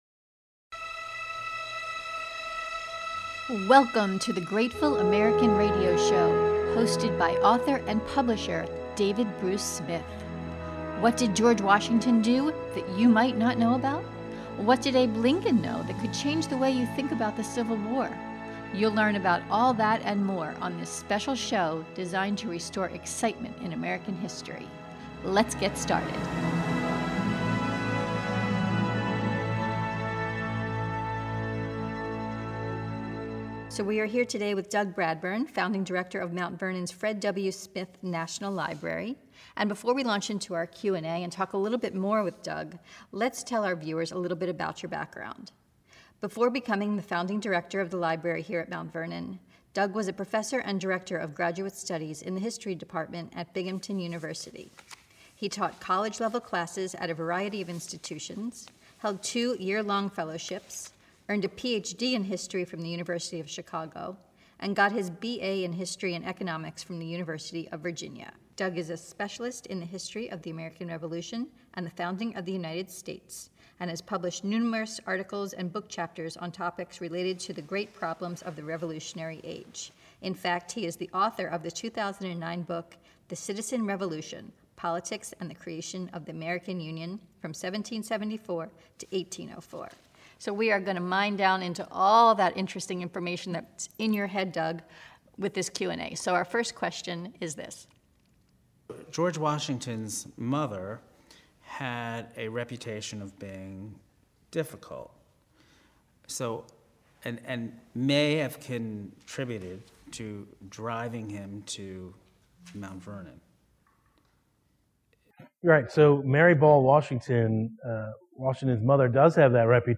interview the specialist on George Washington, who provides insights in the man behind the myth.